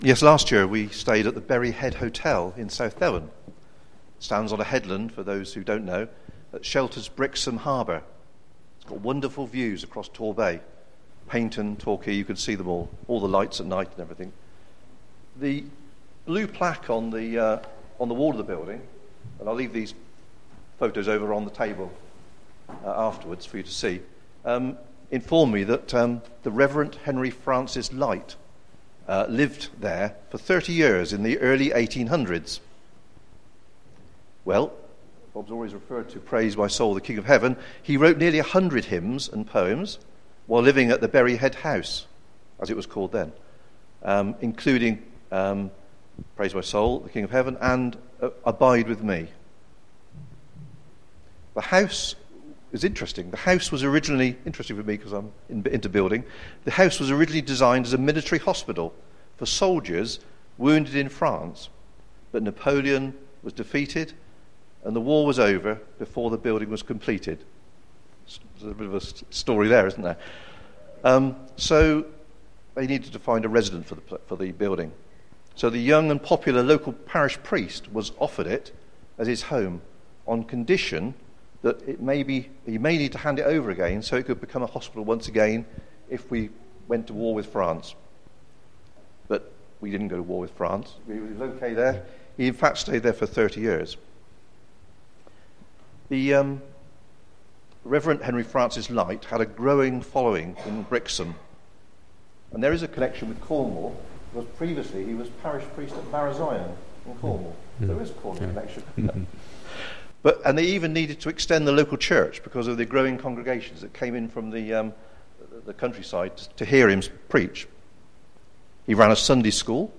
Today’s service celebrates Back to Church Sunday and there are four talks.